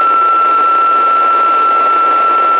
маркер 173.7 в Киеве и области.